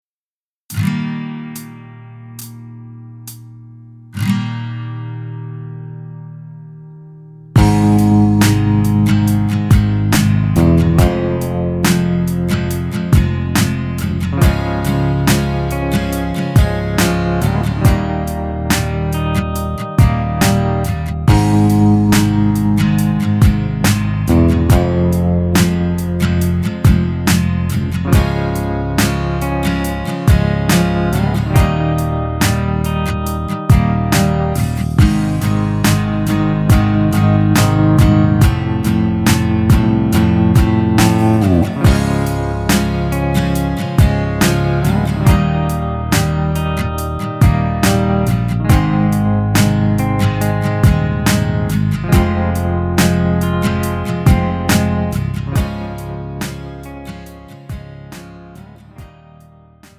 음정 남자키 3:24
장르 가요 구분 Pro MR